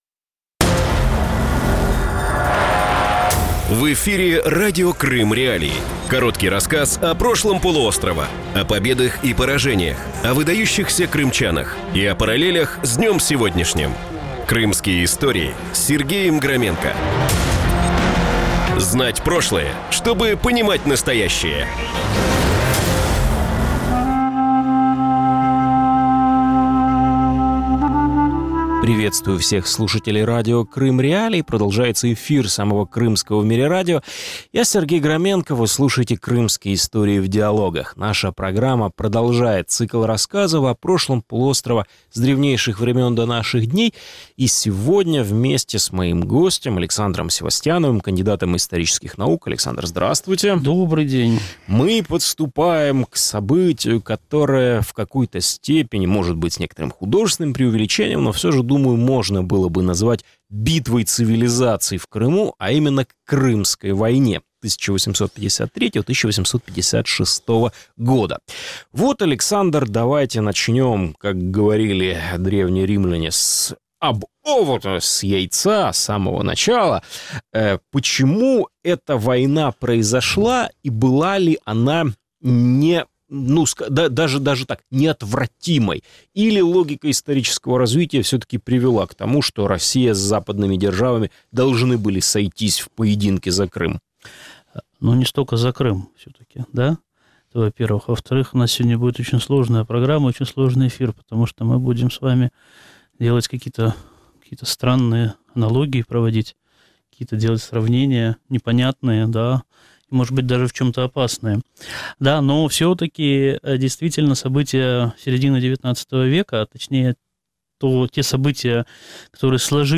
Новый цикл Крымских.Историй в диалогах рассказывает об истории Крыма с древнейших времен до наших дней.